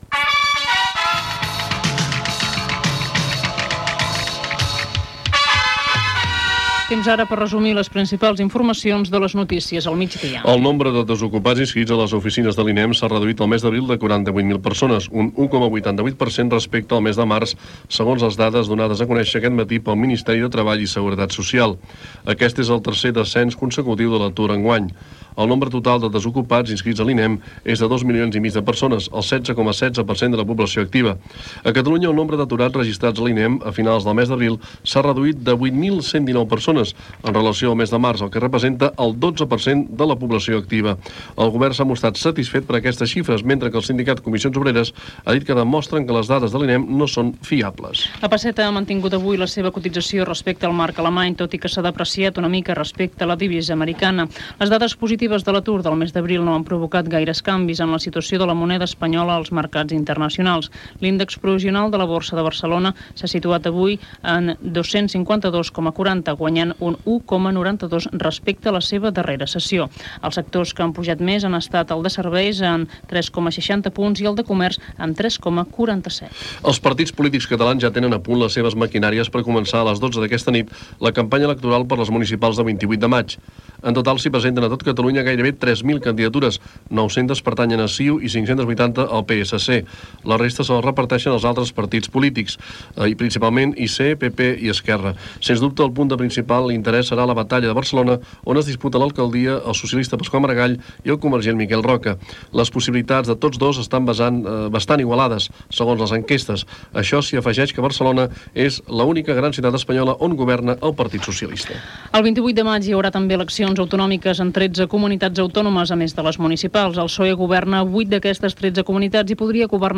Reducció del nombre d'aturats, la borsa, inici de la campanya electoral de les eleccions municipals, eleccions autonòmiques, tràmit de la Llei del jurat, visita del president de la República Txeca a Catalunya, resum de l'actualitat esportiva. Careta de sortida i indicatiu de l'emissora.
Informatiu